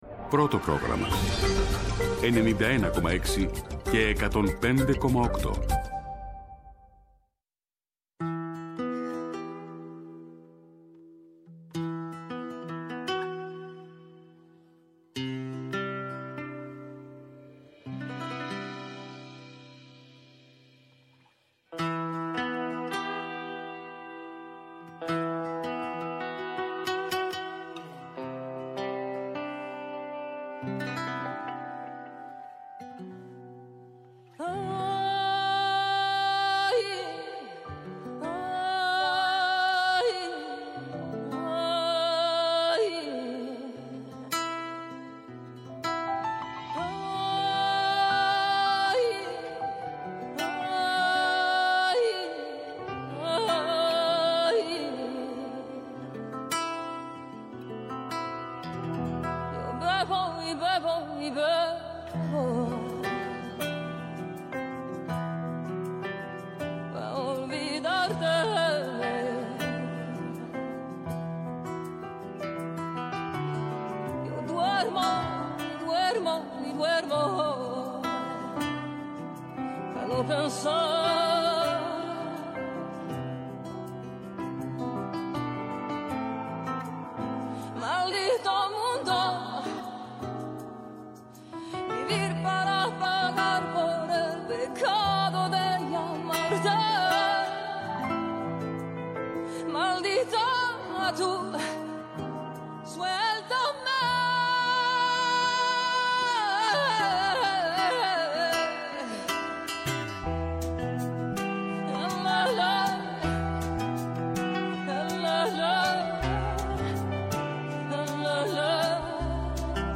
Σήμερα καλεσμένος στο στούντιο της εκπομπής